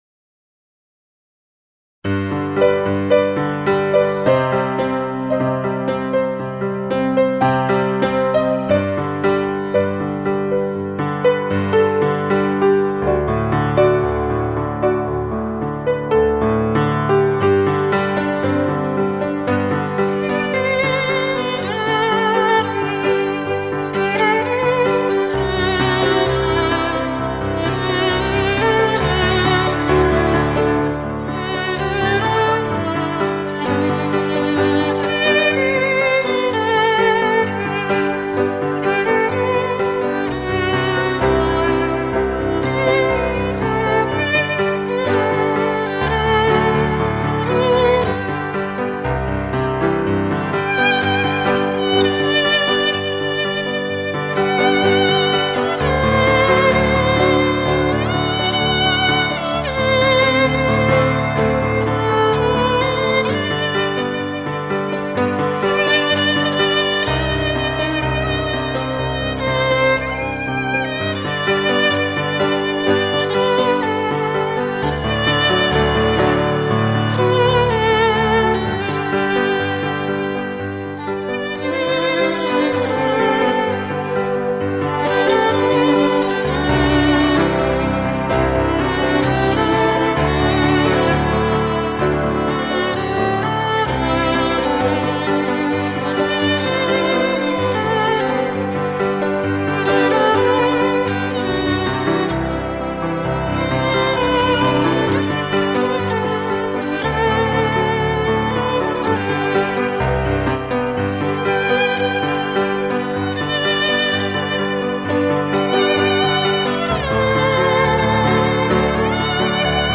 To get a Summertime Violin CD (10 selections, 45 Min), send $5.00 US to: